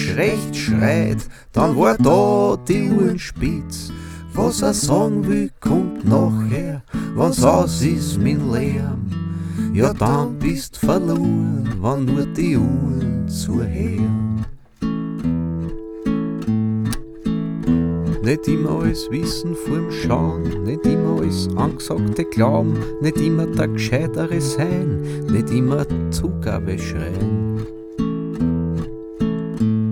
Жанр: Фолк-рок
# German Folk